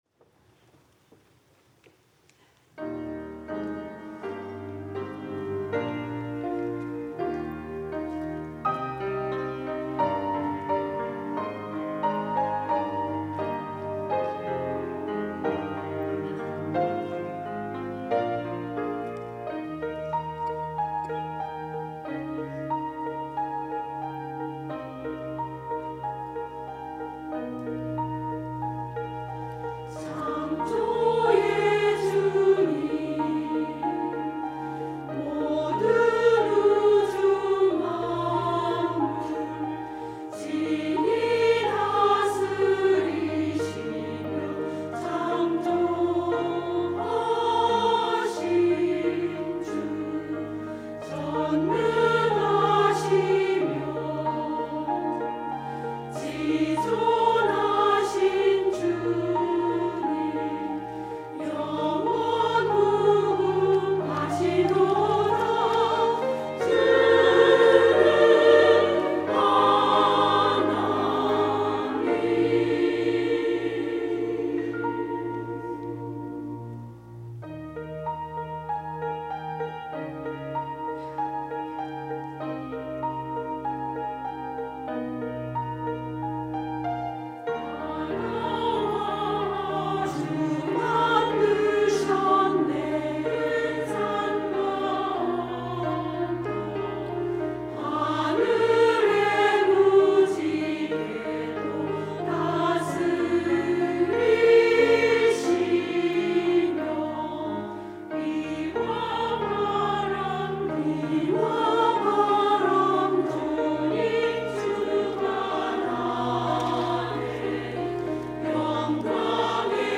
찬양대